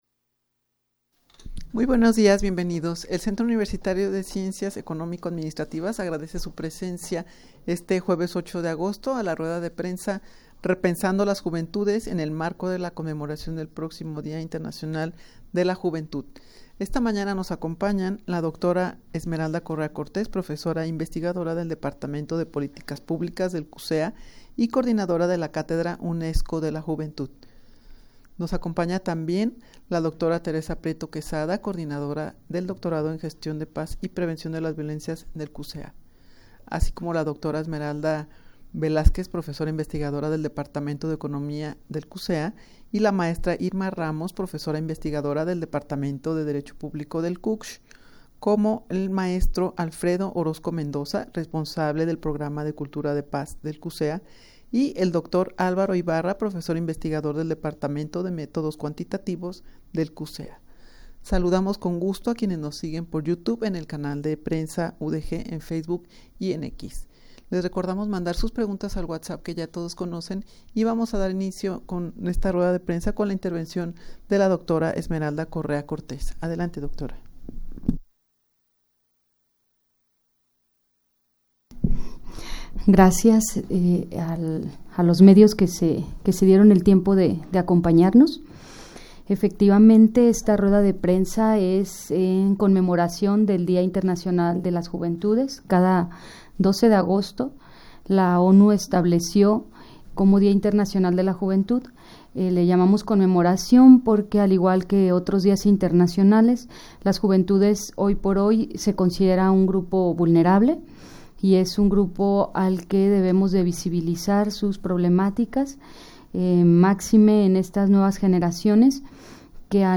En el marco del Día Internacional de la Juventud, que se conmemora el 12 de agosto, profesores de la UdeG comparten su percepción de la salud mental, oportunidades laborales y educativas de los jóvenes
Audio de la Rueda de Prensa
rueda-de-prensa-actividades-para-conmemorar-el-dia-internacional-de-la-juventud.mp3